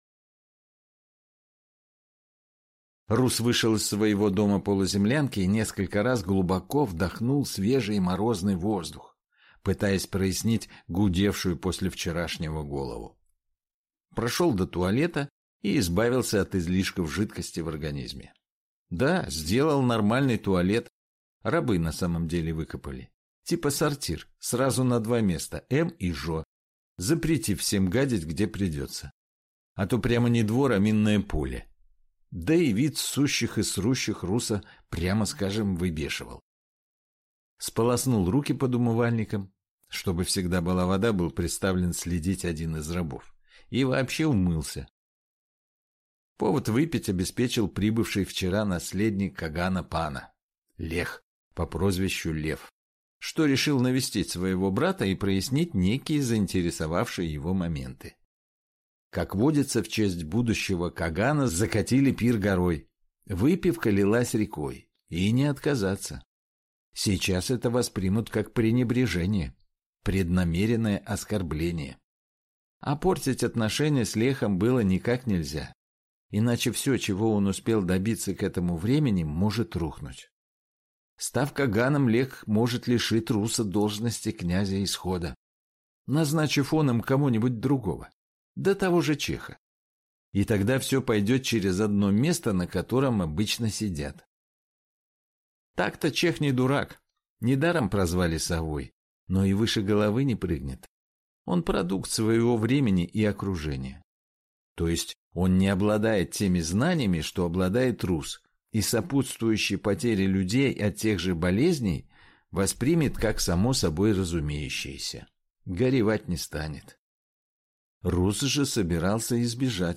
Аудиокнига Князь Рус. Исход | Библиотека аудиокниг